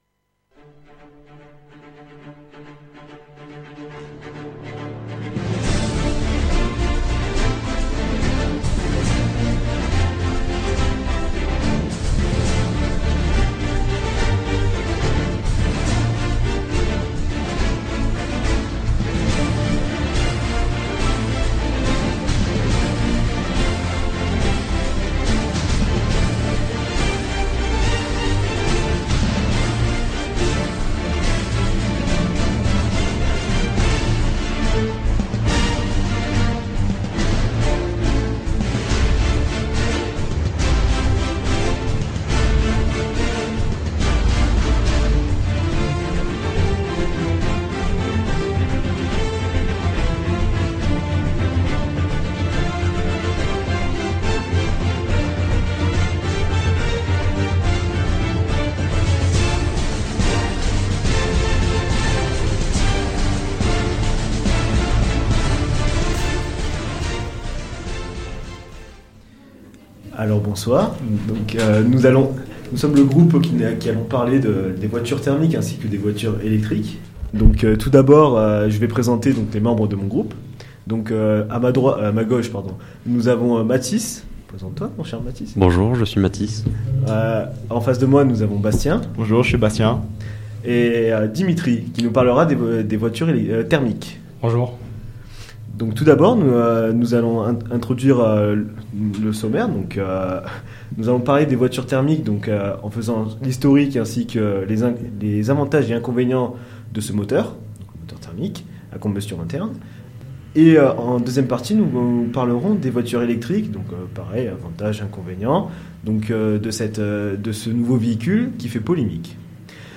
Une émission réalisée entièrement par des étudiants 2ème année Agronomie de l'IUT d'Aix-Marseille Site de Digne-les-Bains saison 2023-2024.